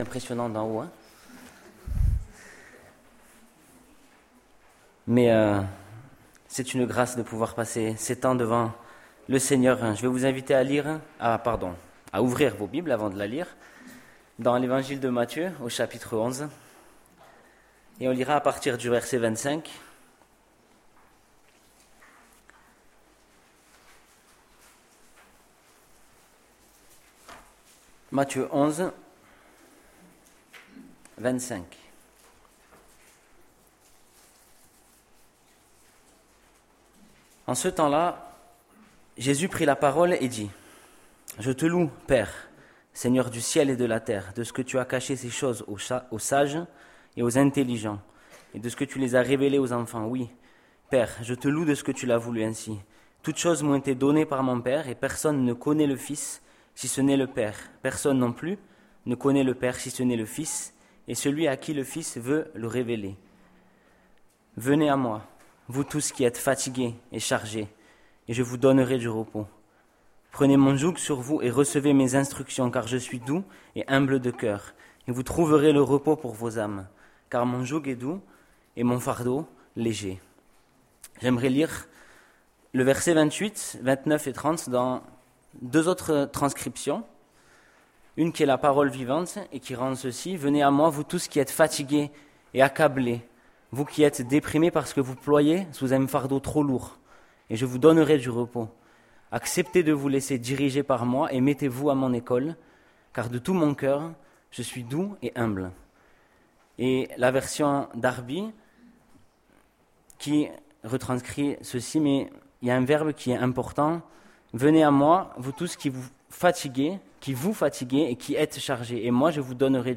Prédication du mardi 07 Juillet 2020